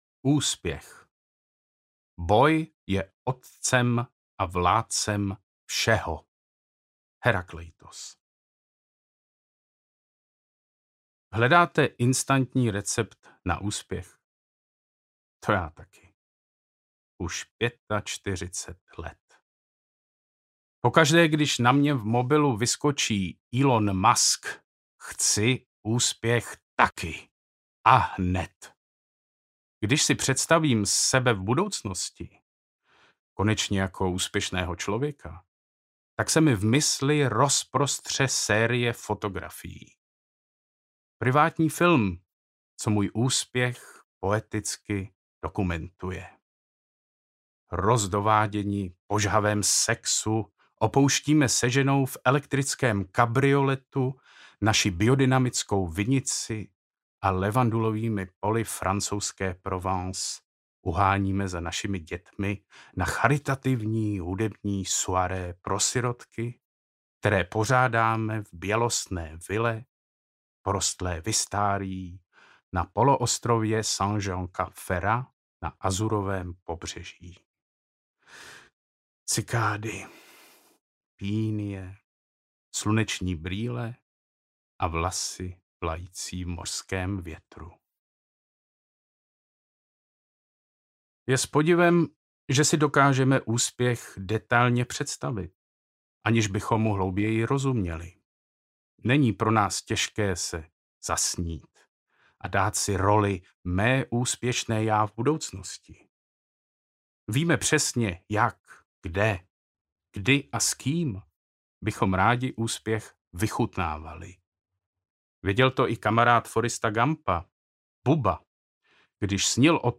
Umění neúspěchu audiokniha
Ukázka z knihy